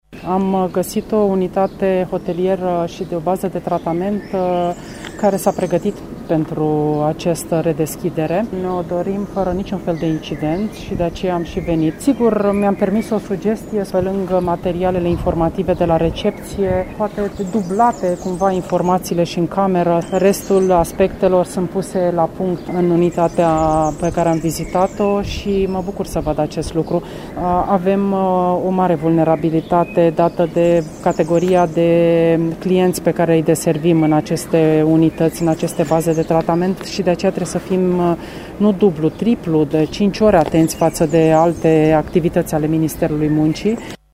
Violeta Alexandru s-a declarat mulțumită de condițiile oferite de baza de tratament din Covasna: